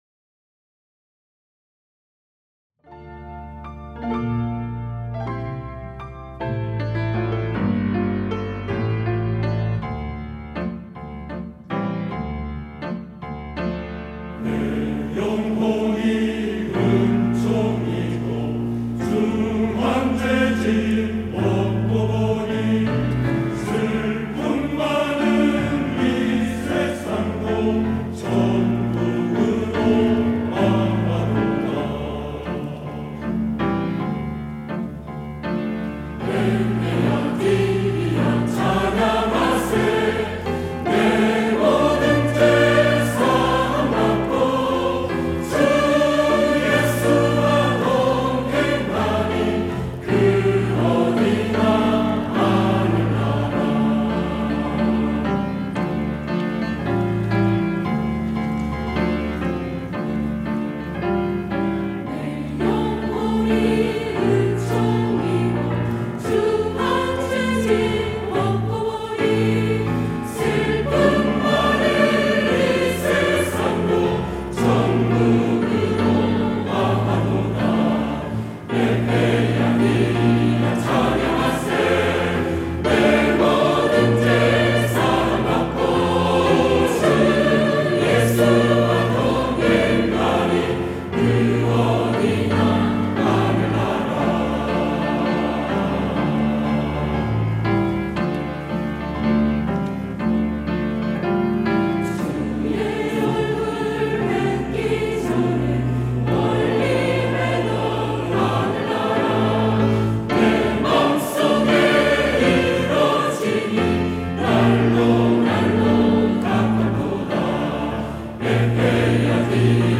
할렐루야(주일2부) - 내 영혼이 은총 입어
찬양대